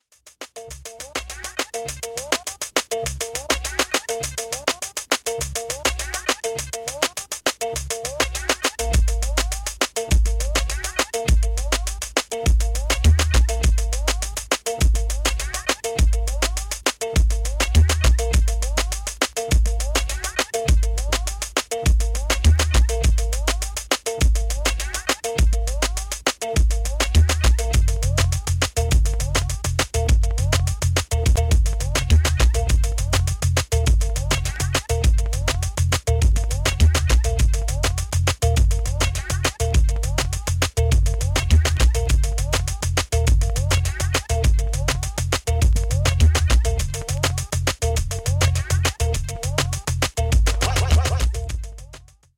Early nineties sample-based Chicago house
House Chicago